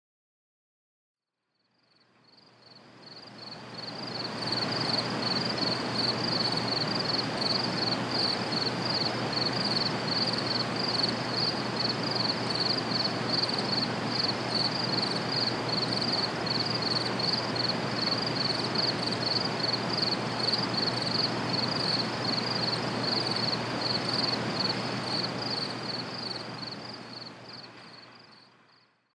دانلود آهنگ جیرجیرک از افکت صوتی انسان و موجودات زنده
جلوه های صوتی
دانلود صدای جیرجیرک از ساعد نیوز با لینک مستقیم و کیفیت بالا